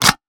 weapon_foley_pickup_01.wav